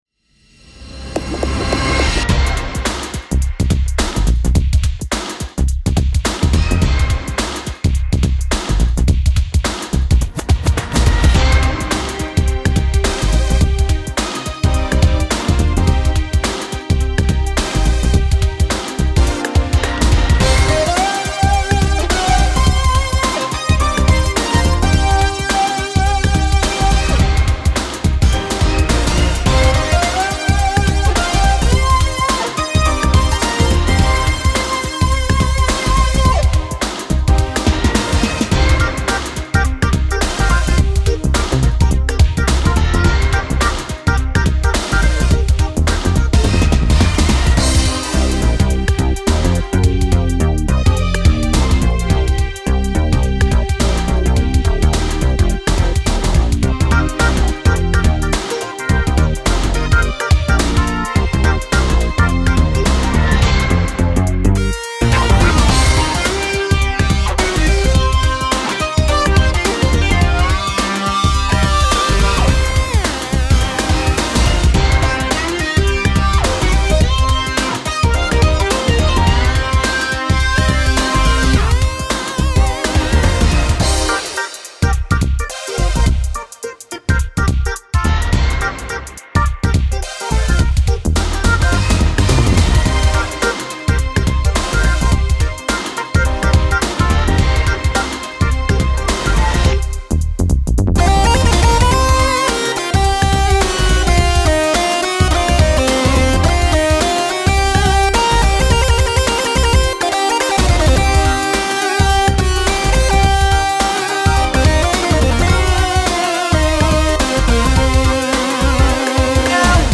Pop
Energetic,Funky